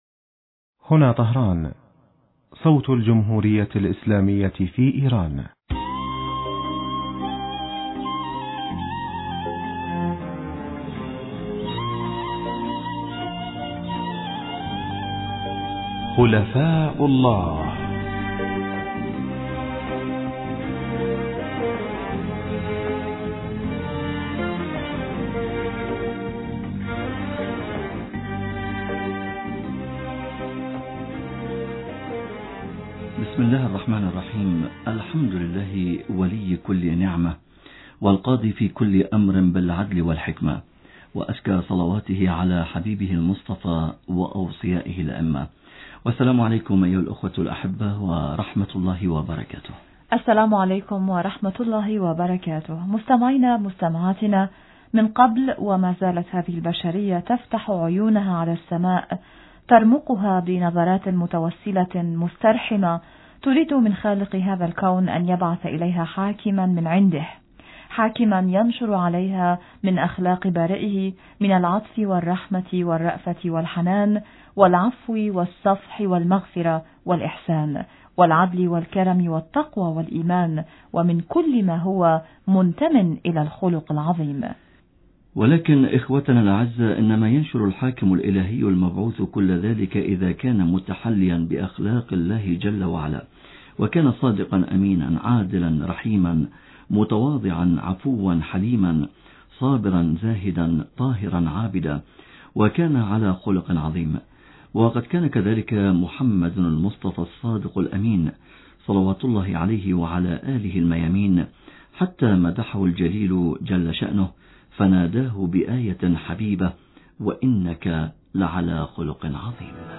الاجابة عن هذا السؤال نتلمسها في الحديث الهاتفي